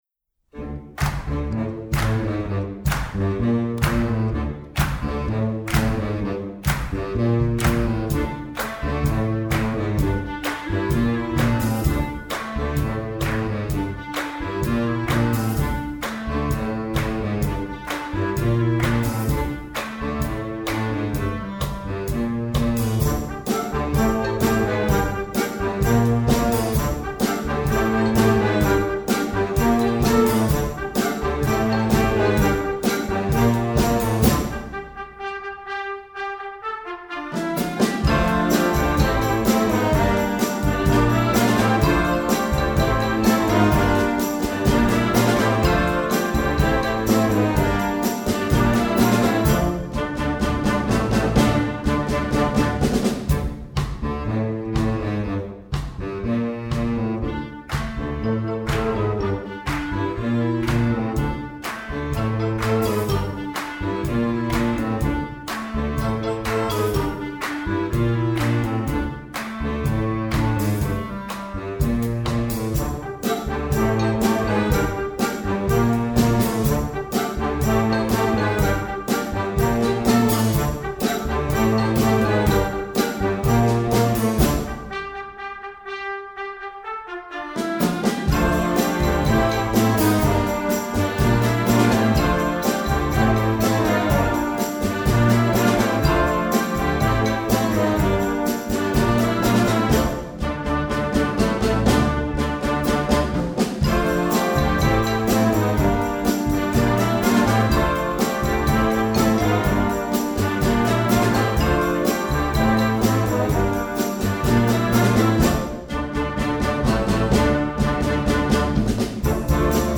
Gattung: Moderner Einzeltitel für Jugendblasorchester
Besetzung: Blasorchester
ansteckender Dance-Rock-Hit